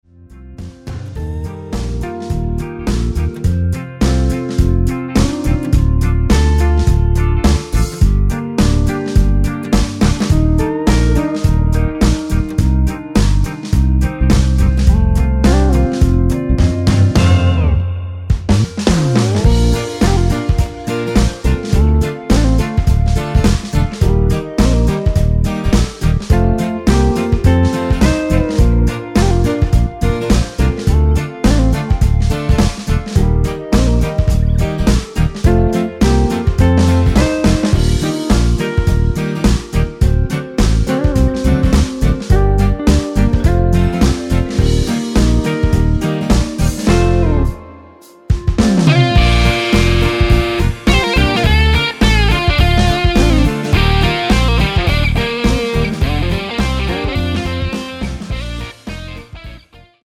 Bb
노래방에서 노래를 부르실때 노래 부분에 가이드 멜로디가 따라 나와서
앞부분30초, 뒷부분30초씩 편집해서 올려 드리고 있습니다.
중간에 음이 끈어지고 다시 나오는 이유는